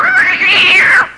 Cat Squeal Sound Effect
Download a high-quality cat squeal sound effect.
cat-squeal-1.mp3